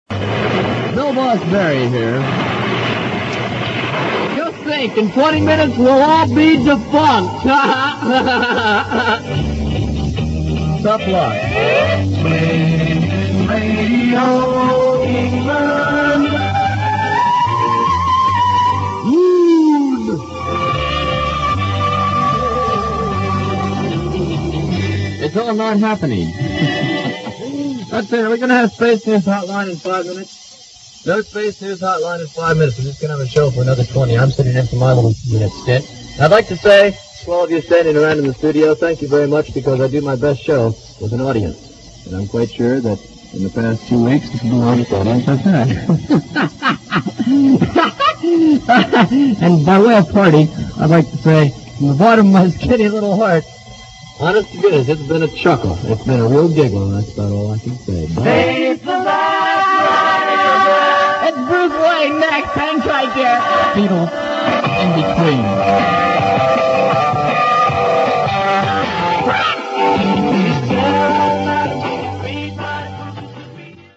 He doesn't sound too upset (duration 1 minute 13 seconds)